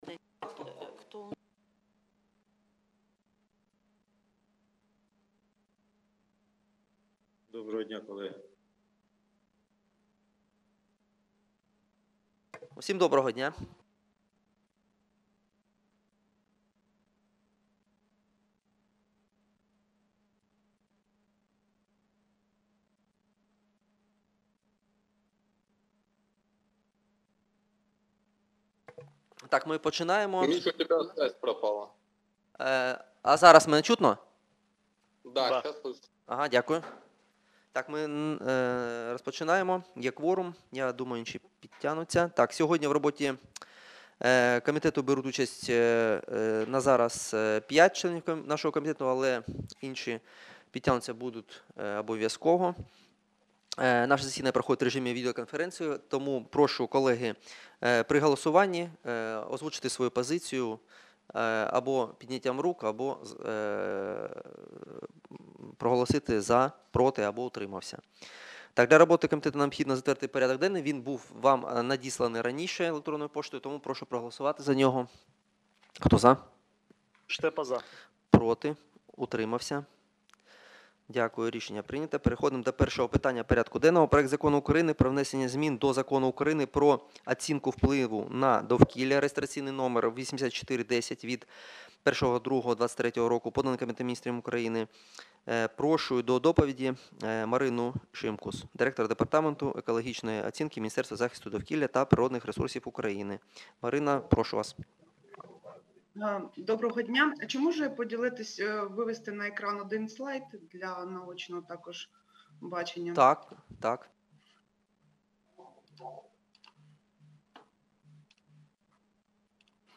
Аудіозапис засідання Комітету від 23.03.2023